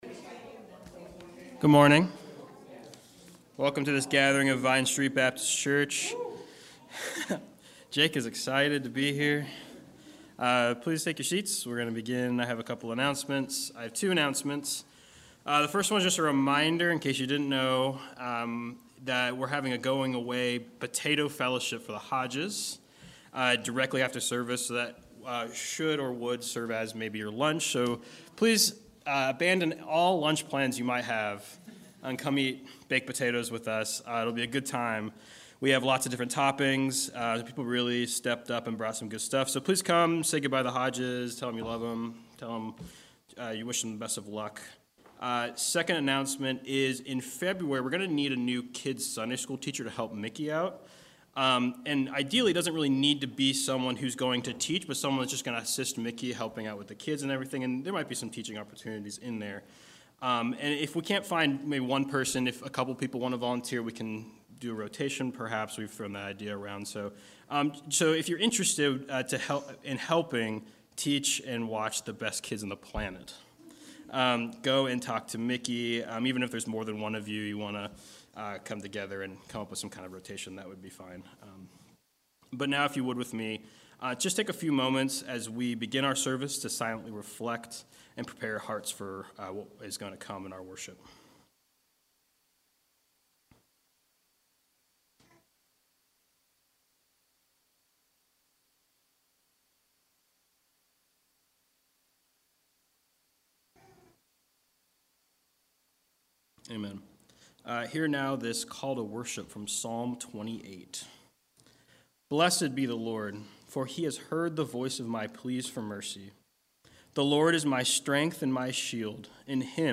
November 13 Worship Audio – Full Service